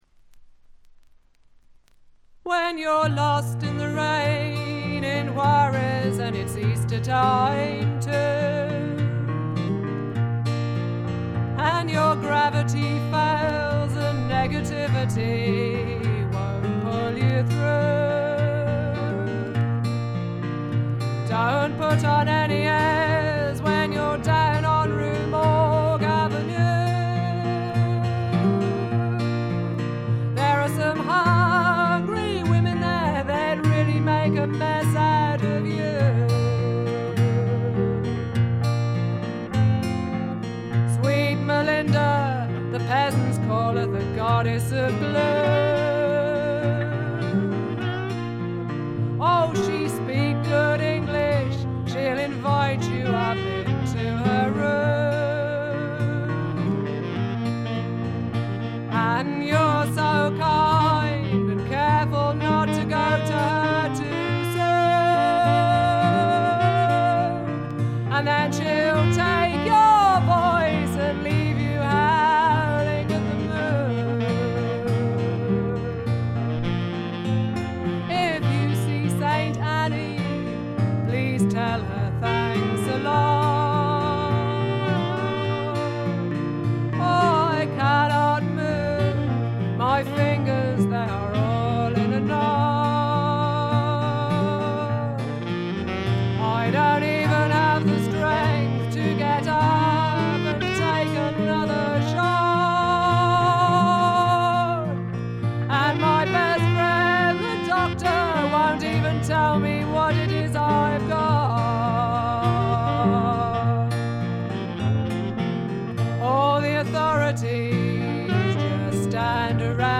英国の女性フォークシンガー／ギタリスト。
味のあるアルとト・ヴォイスで淡々と歌っていクールなものです。
試聴曲は現品からの取り込み音源です。
Vocals, Guitar, Electric Bass, Banjo